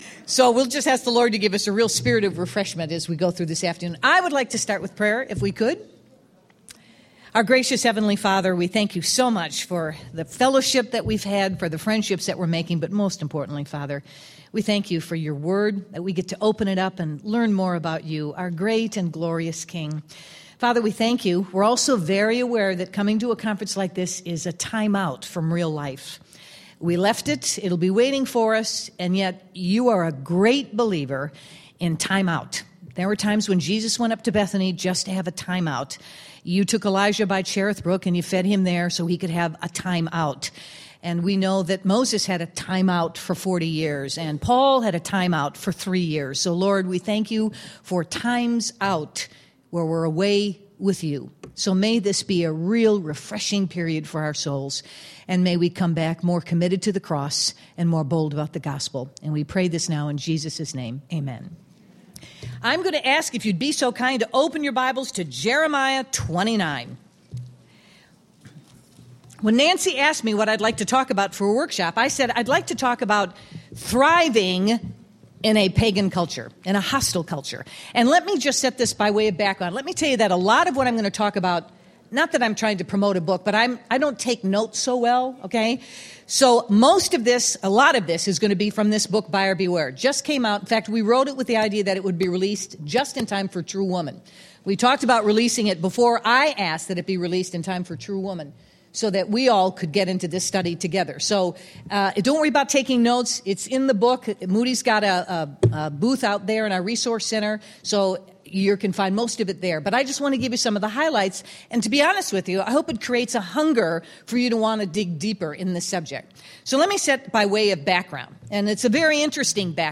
Thriving in a Hostile Culture | True Woman '12 | Events | Revive Our Hearts